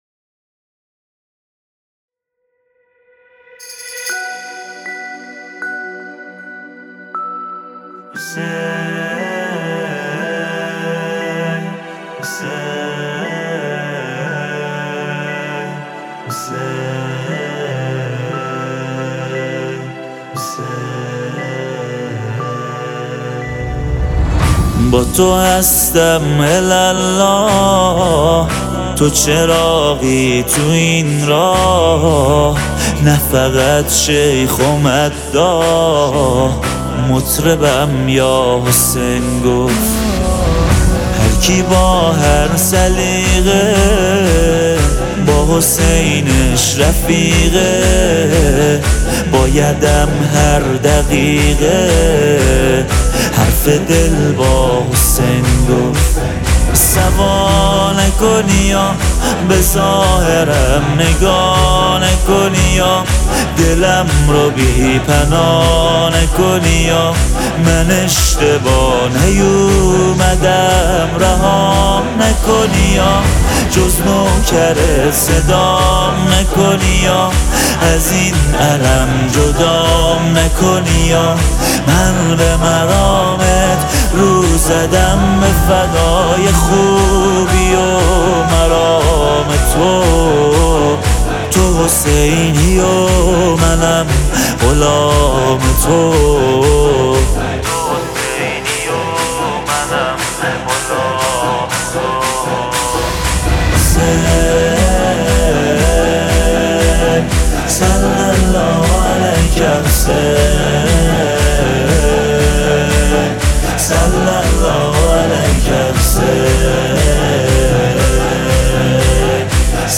مداحی_اربعین حسینی